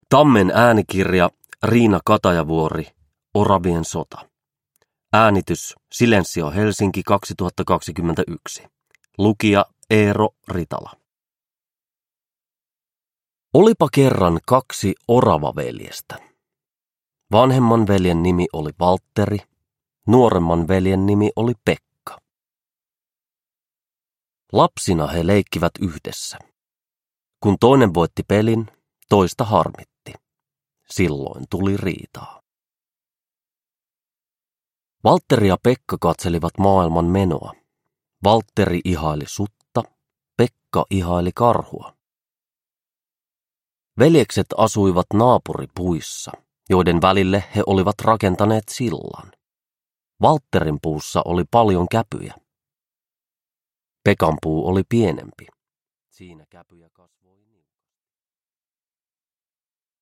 Oravien sota – Ljudbok – Laddas ner
Uppläsare: Eero Ritala